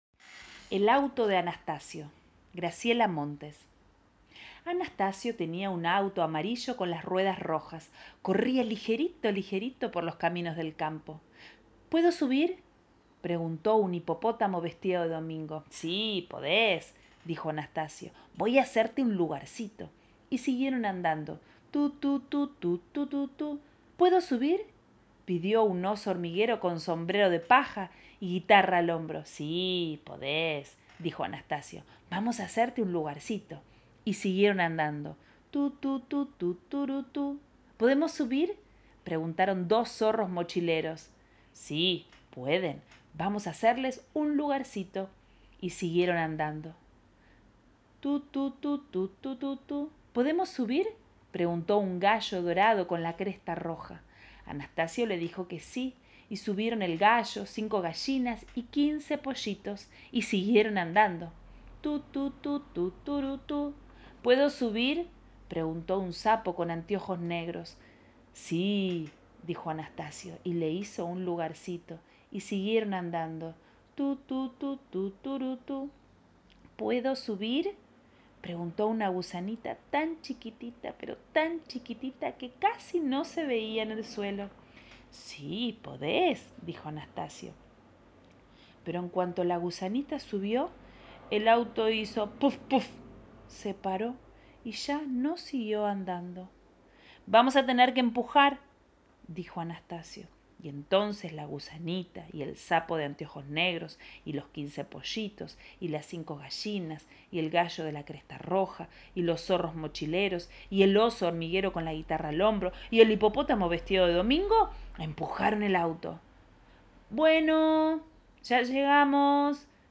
Es una corta historia para llevar a los más peques al viaje de la narración